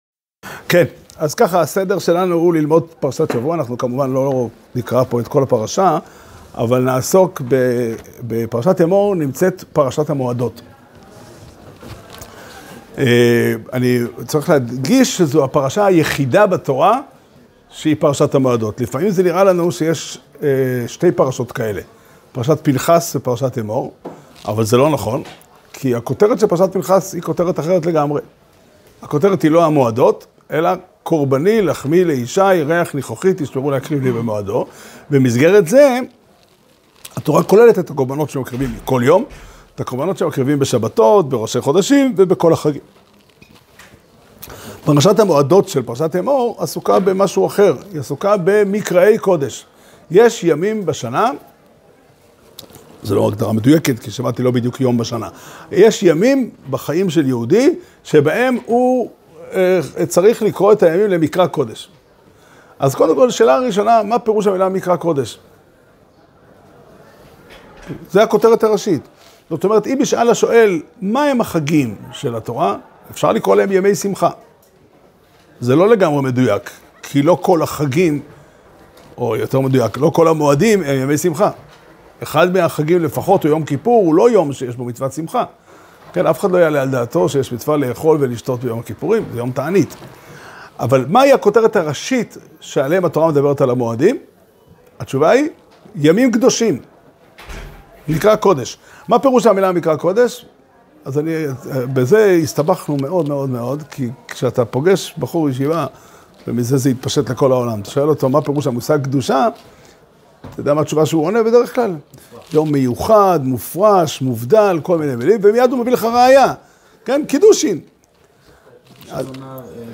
שיעור שנמסר בבית המדרש פתחי עולם בתאריך ו' באייר תשפ"ד